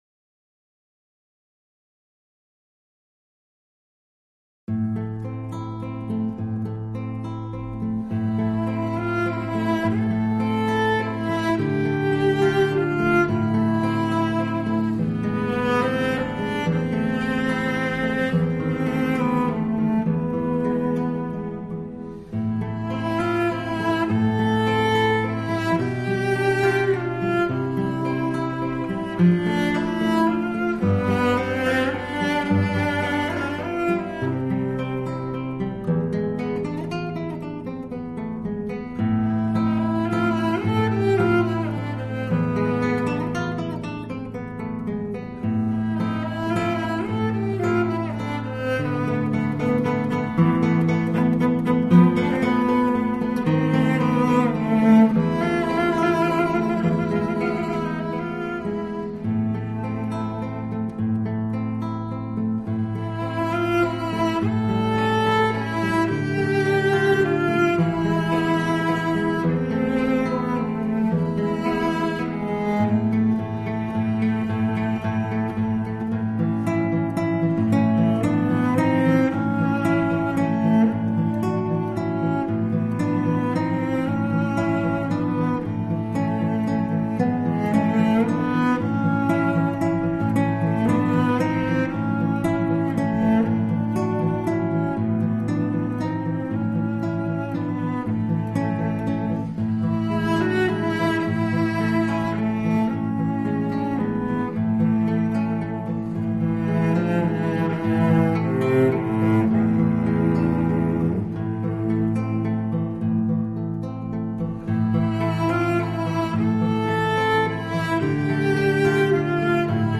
大提琴的柔、吉他的美，如此稀有的音樂組合出來之不同美感經驗，一 定會讓您愛不釋手，是一張絕對值得您聆聽的古典室內樂小品。